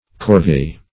Corvee \Cor`vee"\ (k?r`v" or -v?"), n. [F. corv['e]e, fr. LL.